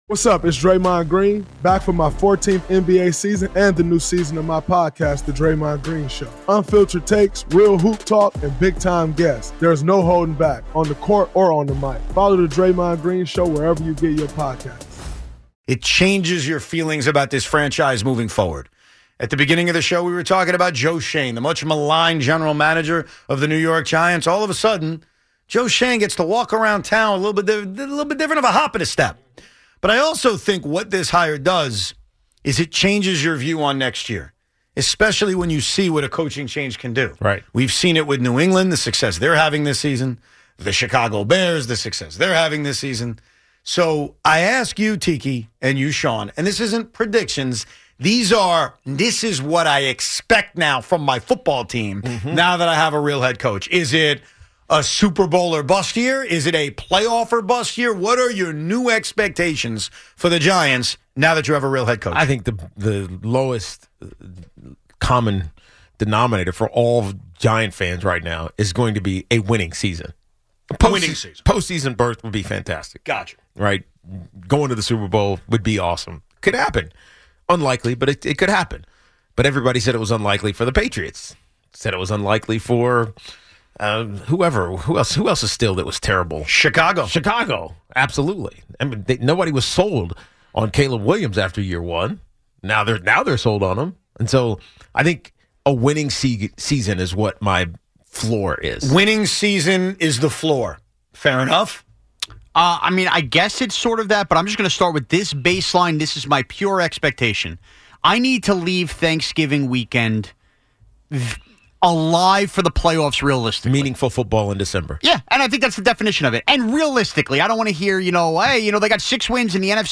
RSS 🧾 Download transcript Summary With a real head coach now in place, the entire Giants conversation shifts from “hope” to “expectation.” The guys debate what the floor actually is in 2026: simply a winning season, meaningful football in December, or a flat-out playoff mandate.
Plus, callers weigh in on whether this feels like a true turning point, and why Harbaugh should inspire more confidence than past “winning coaches” like Dan Reeves.